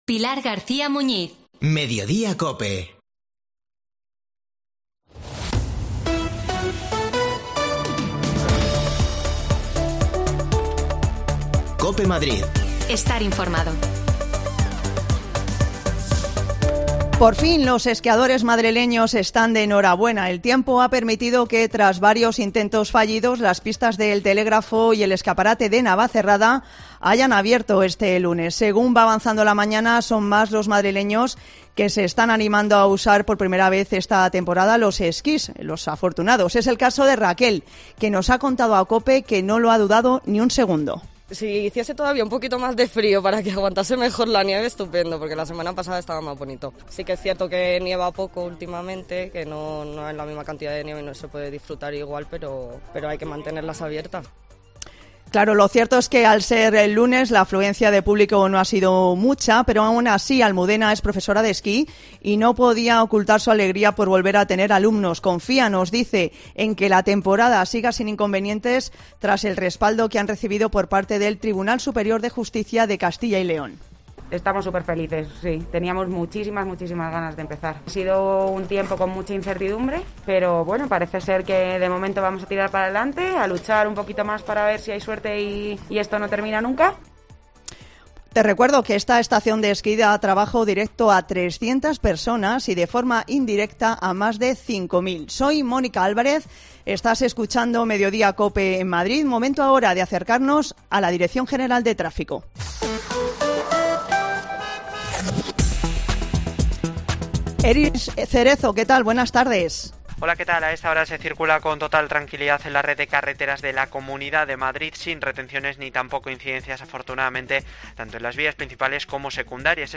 La estación de esqui de Navacerrada ha abierto hoy por fin, después de muchos obstáculos. Hablamos con esquiadores que ya la han podido disfrutar esta mañana